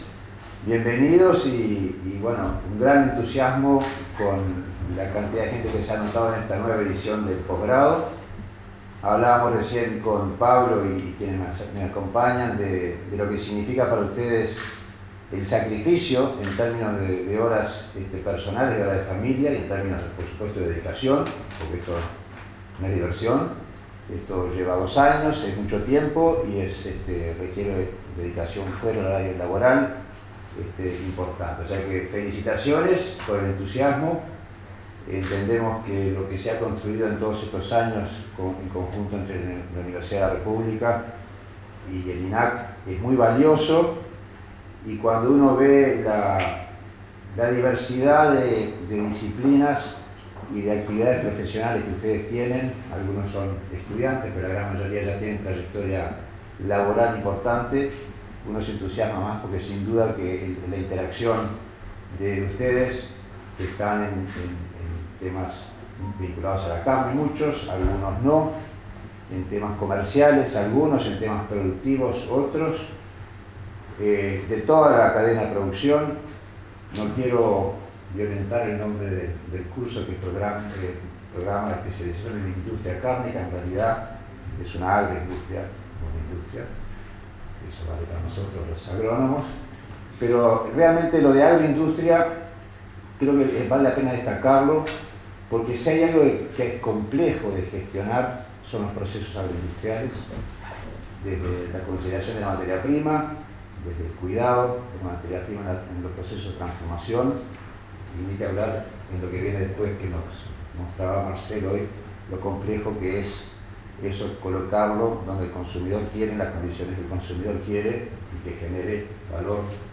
Luego se llevó a cabo la ceremonia de apertura